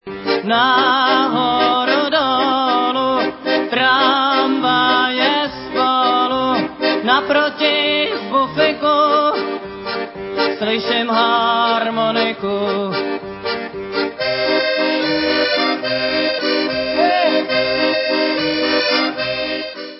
Záznam koncertu
sledovat novinky v oddělení Folk